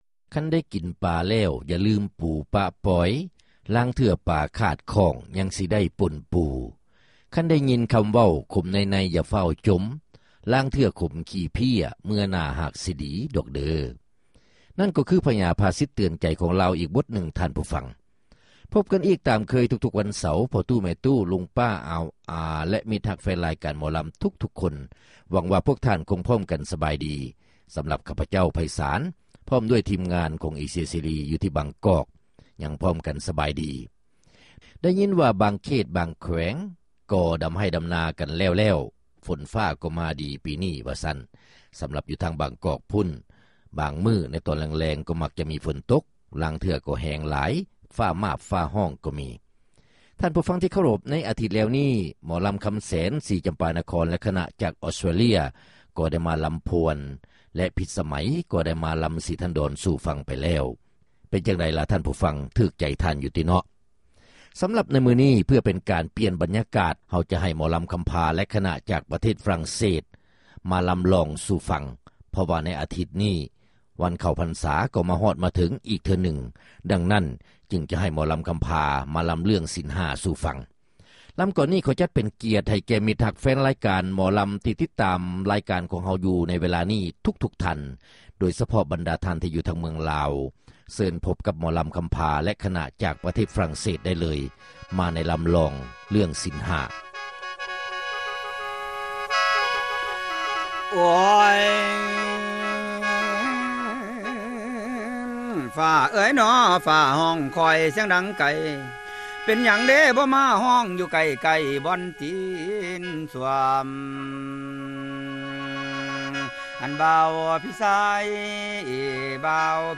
ຣາຍການໜໍລຳ ປະຈຳສັປະດາ ວັນທີ 22 ເດືອນ ກໍຣະກະດາ ປີ 2005